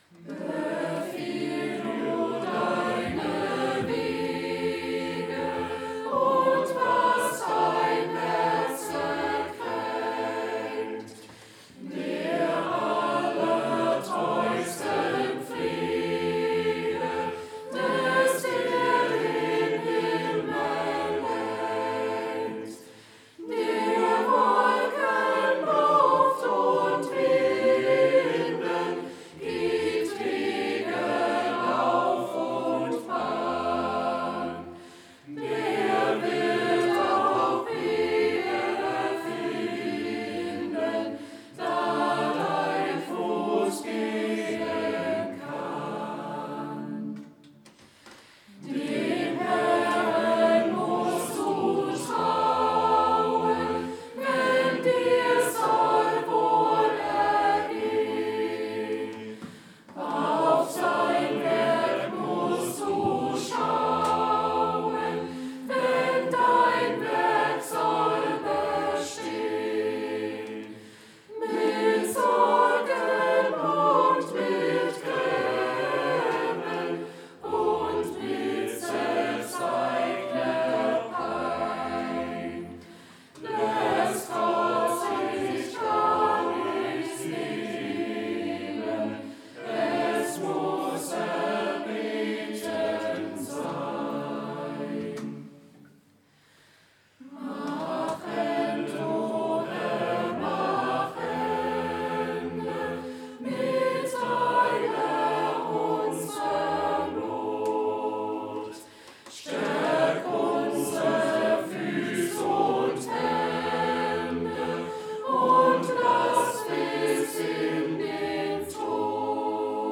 Befiehl du deine Wege... Chor der Ev.-Luth. St. Johannesgemeinde Zwickau-Planitz
Audiomitschnitt unseres Gottesdienstes vom Vorletzten Sonntag im Kirchenjahr 2024